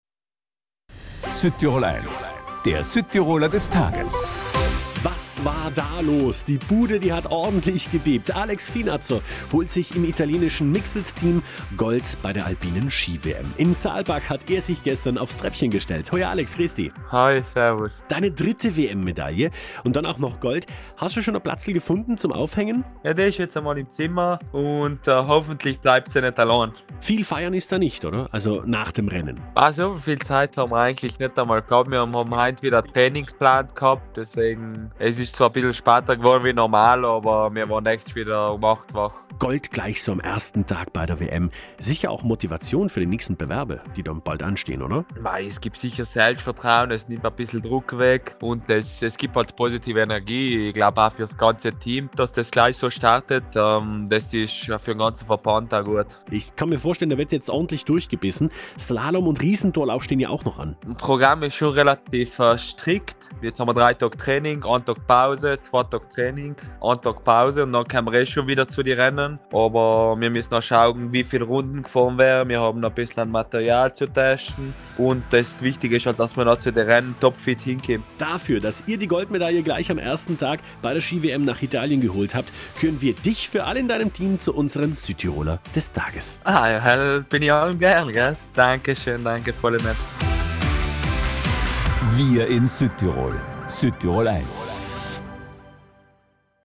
Das komplette Interview mit Alex Vinatzer gibt’s hier!